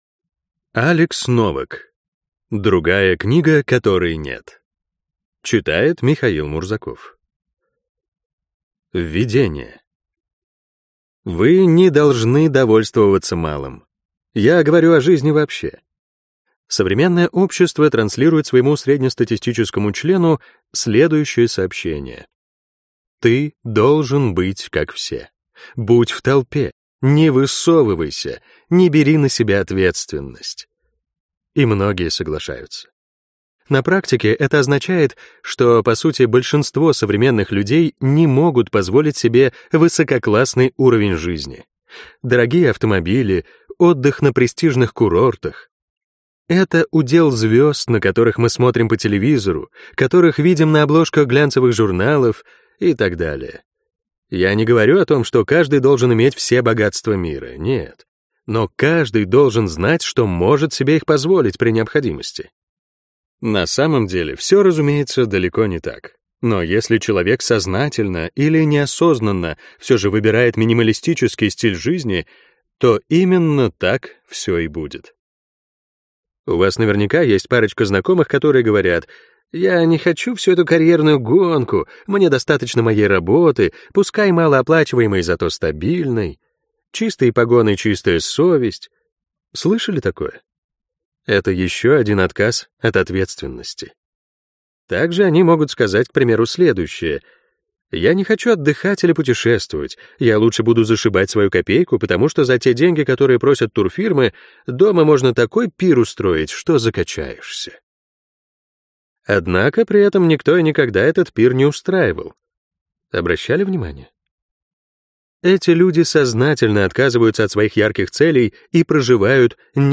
Аудиокнига Другая книга, которой нет. 20 наиболее эффективных инструментов саморазвития - купить, скачать и слушать онлайн | КнигоПоиск